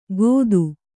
♪ gōdu